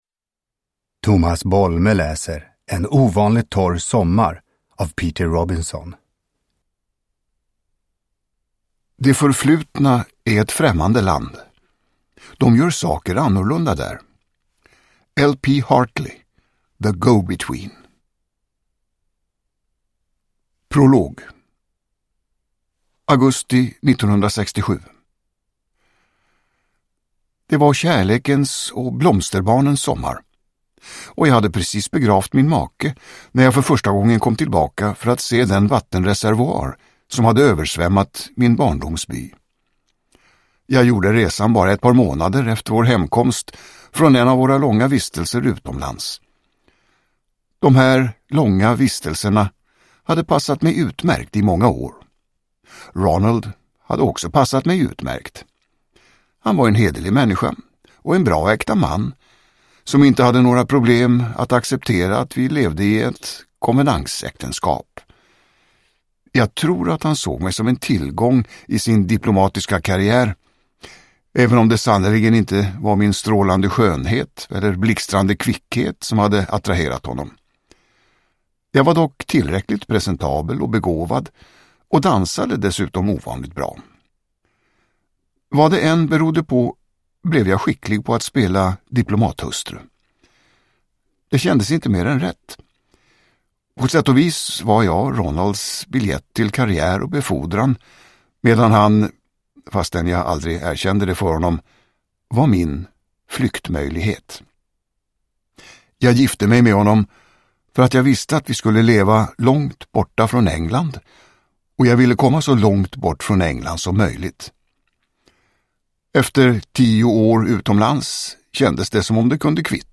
En ovanligt torr sommar – Ljudbok – Laddas ner
Uppläsare: Tomas Bolme